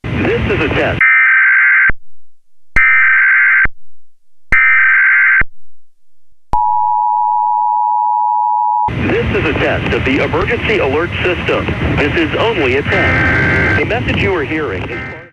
Blaring Announcement
The raucous noise, obviously designed to get attention, is followed by a message which would presumably contain instructions about what to do in the event of an actual emergency. The first necessity is to pay attention, then after the noise comes the message.
National_EAS_Test_1.mp3